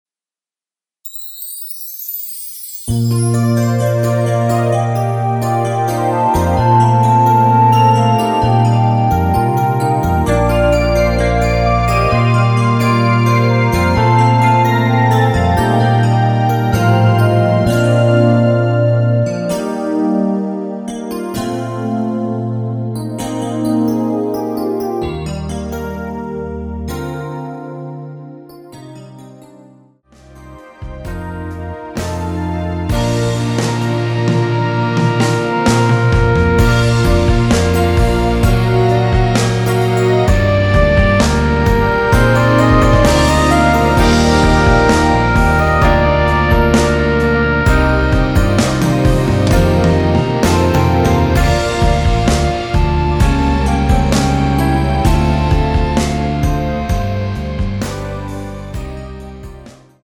대부분의 여성분이 부르실수 있는 키로 제작 하였습니다.
Bb
앞부분30초, 뒷부분30초씩 편집해서 올려 드리고 있습니다.